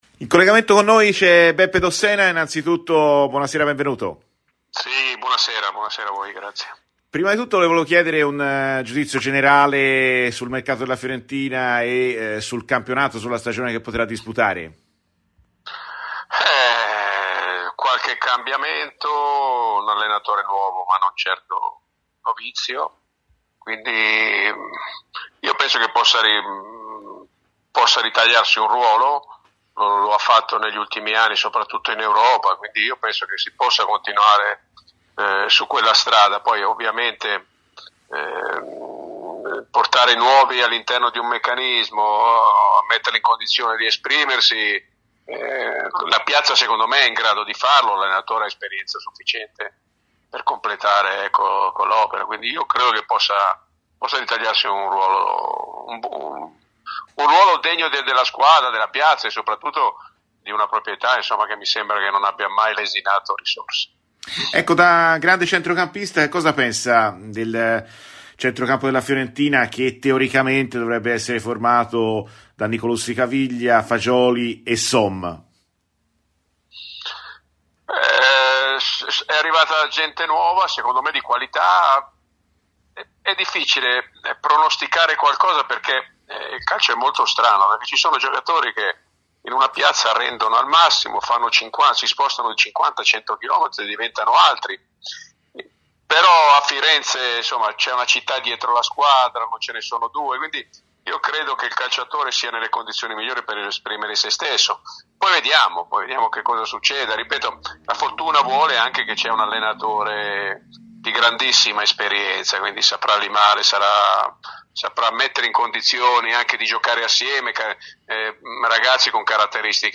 Giuseppe Dossena, ex calciatore e allenatore, ha rilasciato le seguenti dichiarazioni a Radio FirenzeViola nel corso della trasmissione "Viola amore mio":